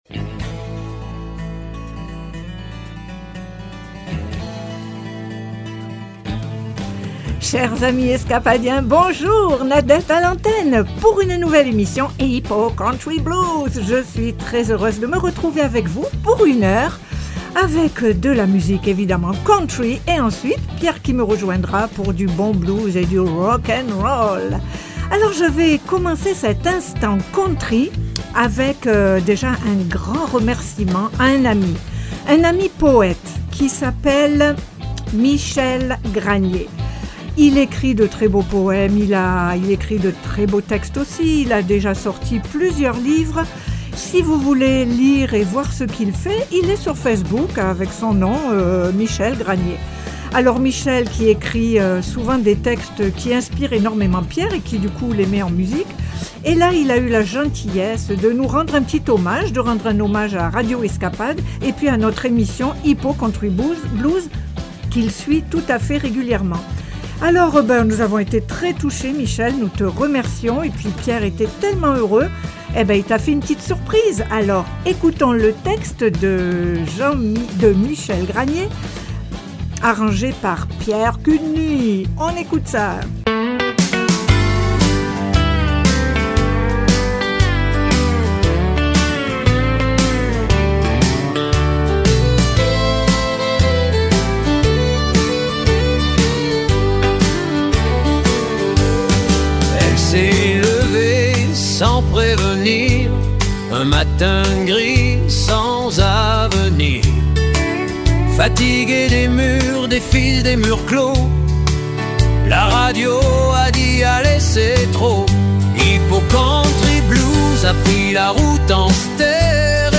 02. 09/05 De la country et du blues!